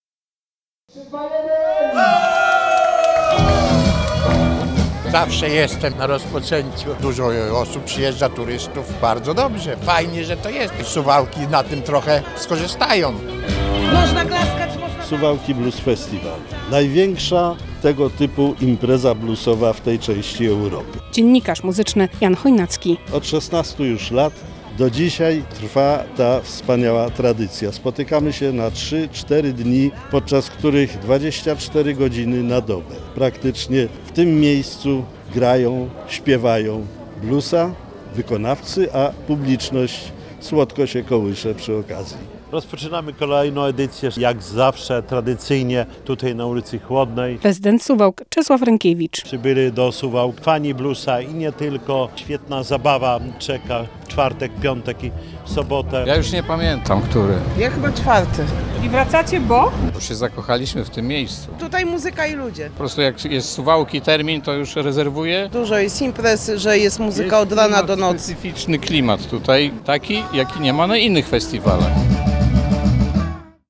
Rozpoczęła się 16. edycja Suwałki Blues Festival - relacja